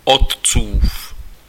Ääntäminen
Synonyymit pater Ääntäminen France: IPA: [pa.tɛʁ.nɛl] Haettu sana löytyi näillä lähdekielillä: ranska Käännös Ääninäyte Adjektiivit 1. otcovský {m} 2. otcův Suku: m .